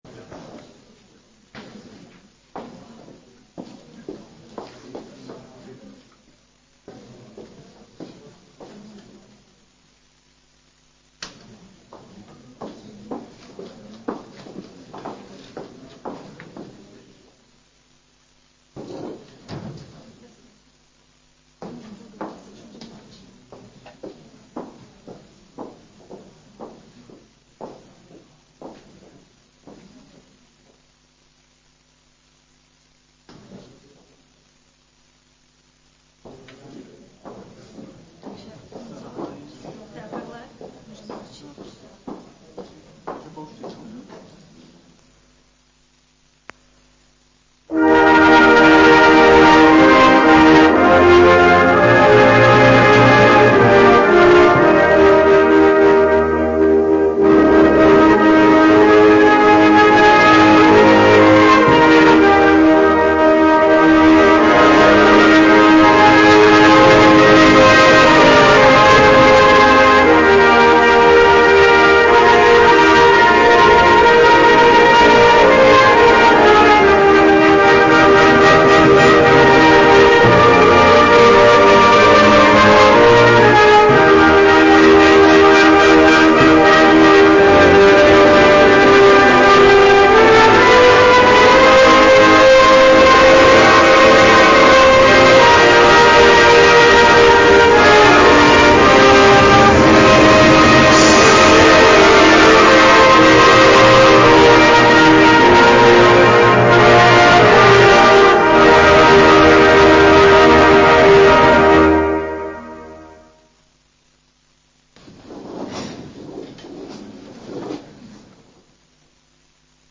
Město Litvínov: Ustavující zasedání Zastupitelstva města 14.10.2022 d67bb0bdb185002b029f33f67b5398a7 audio